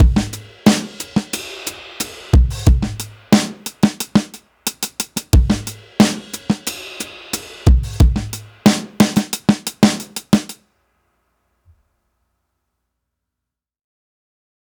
Unison Jazz - 8 - 90bpm.wav